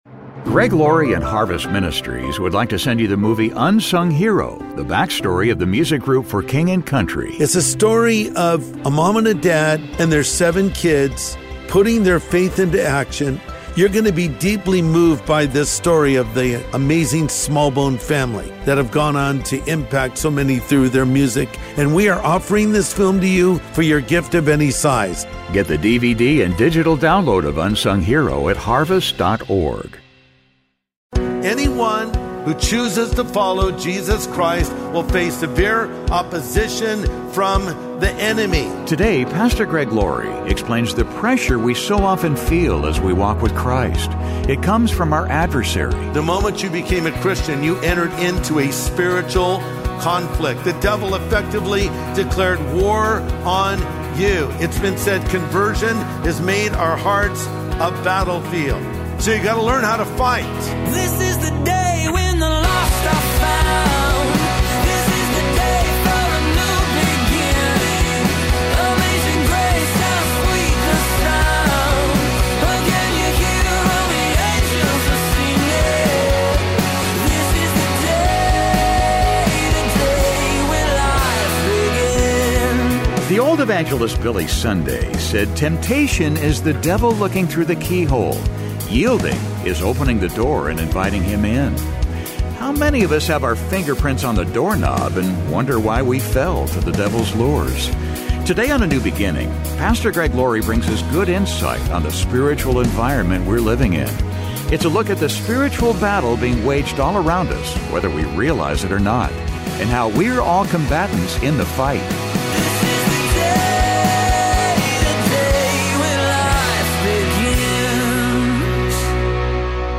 Today on A NEW BEGINNING, Pastor Greg Laurie brings us good insight on the spiritual environment we're living in. It's a look at the spiritual battle being waged all around us, whether we realize it or not . . . and how we're all combatants in the fight.